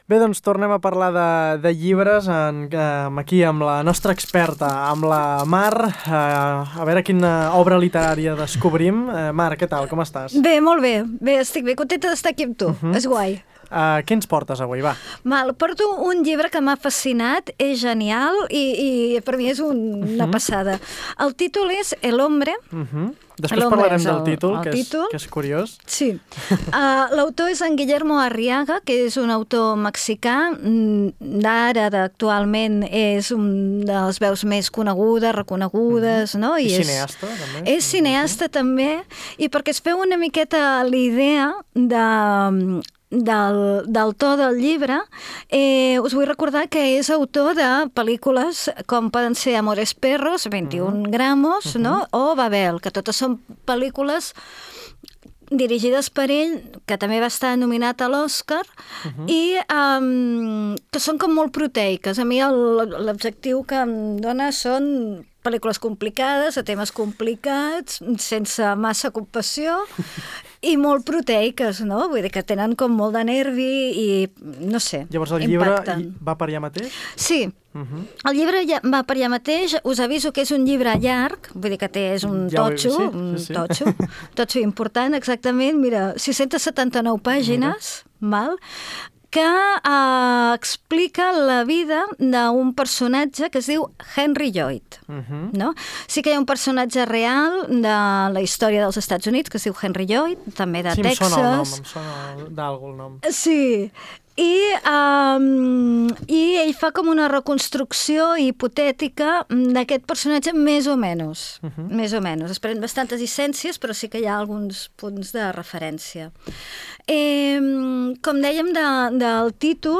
Al llarg de la conversa, aprofundim en els temes que planteja l’obra, el seu to i el context en què s’inscriu, tot reflexionant sobre per què pot resultar una lectura suggeridora. Una proposta literària que convida a la interpretació i al pensament crític, de la mà d’una mirada experta i propera.